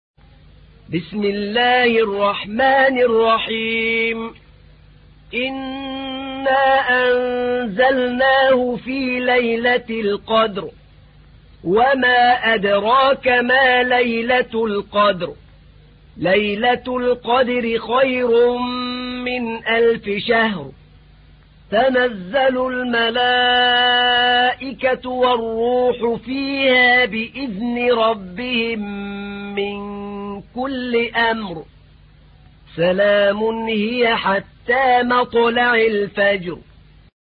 تحميل : 97. سورة القدر / القارئ أحمد نعينع / القرآن الكريم / موقع يا حسين